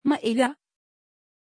Prononciation de Maélia
pronunciation-maélia-tr.mp3